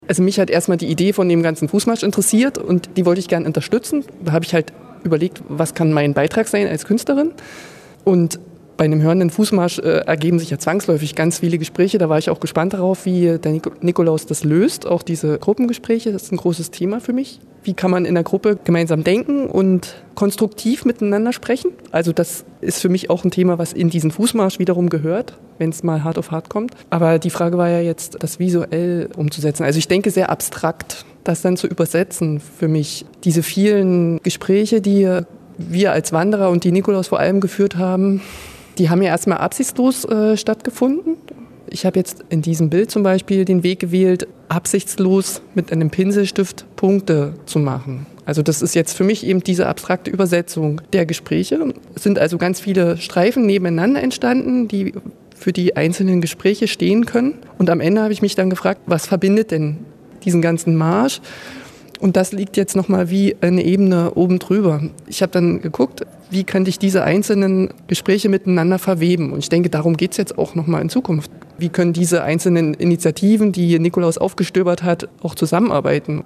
MDR Thüringen_31_05_2013_Gespräch zum Bild